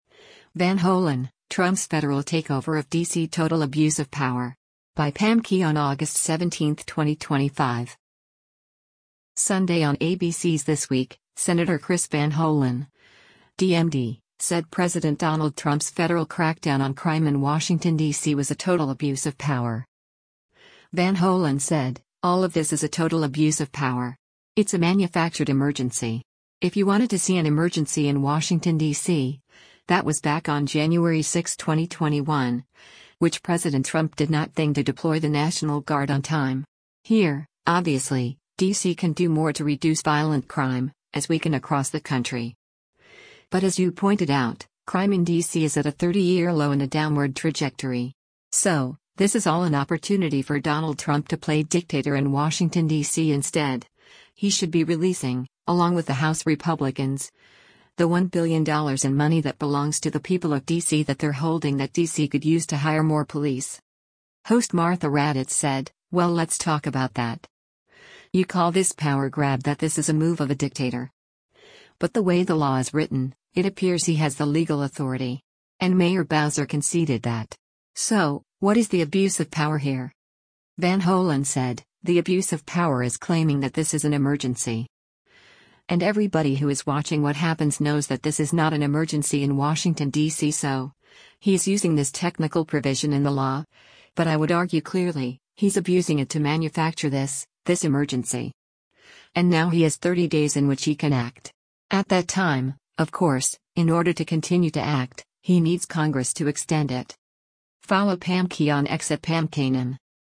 Sunday on ABC’s “This Week,” Sen. Chris Van Hollen (D-MD) said President Donald Trump’s federal crackdown on crime in Washington D.C. was “a total abuse of power.”